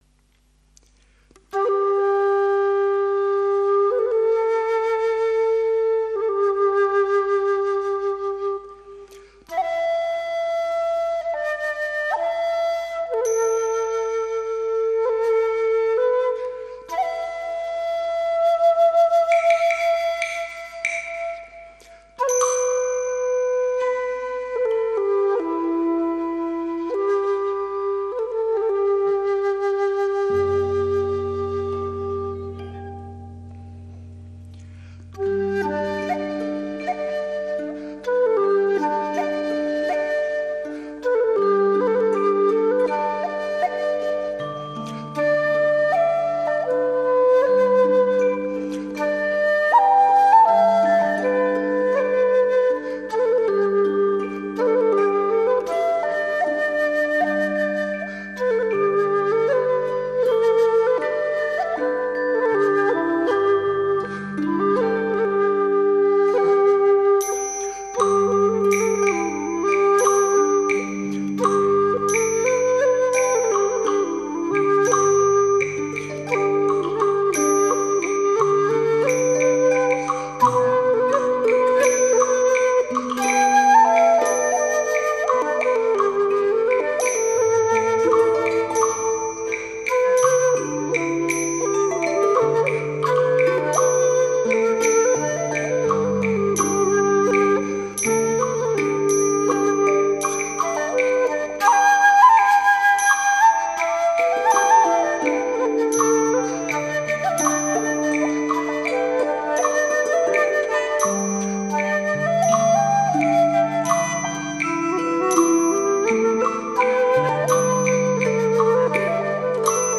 [原创]箫独奏：梅花三弄